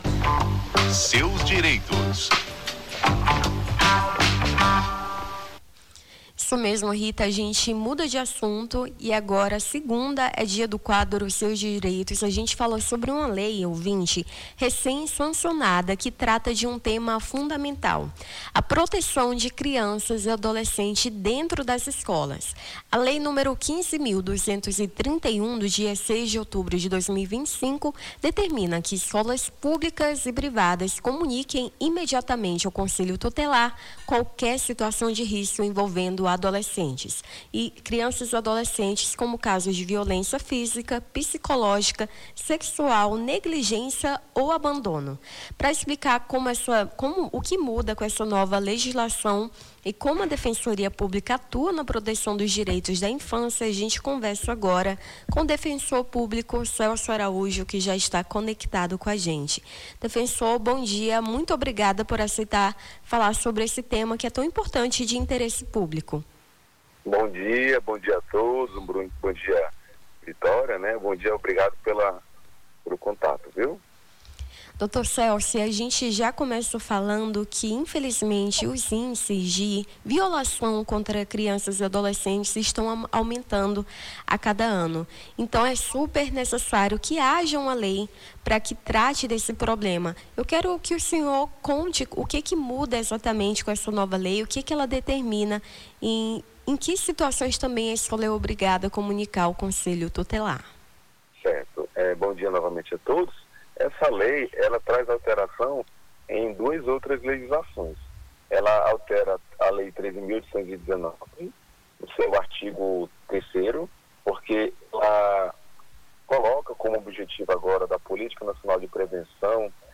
O Jornal da Manhã conversou com o defensor público Celso Araújo sobre a lei nº 15.231, de 6 de outubro de 2025, que determina que escolas públicas e privadas devem comunicar imediatamente ao conselho tutelar qualquer situação de risco envolvendo estudantes, como casos de violência física, psicológica, sexual, negligência ou abandono.